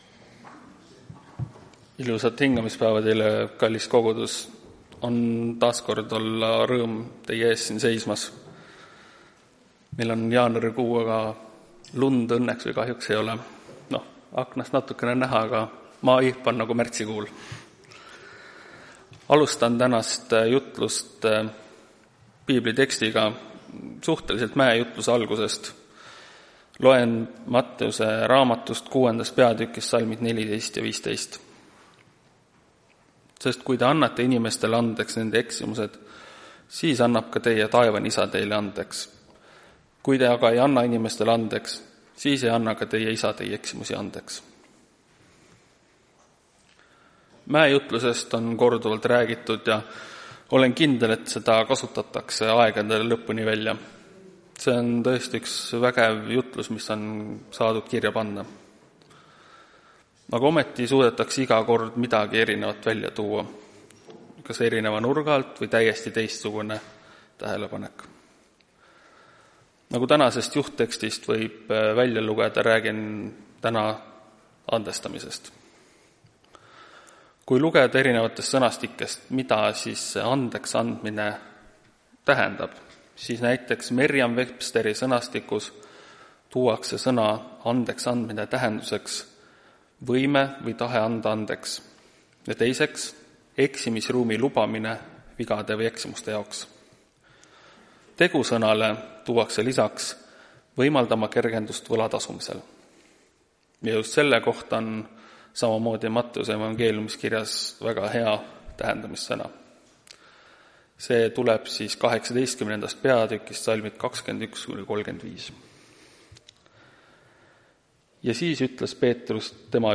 Tartu adventkoguduse 25.01.2025 hommikuse teenistuse jutluse helisalvestis.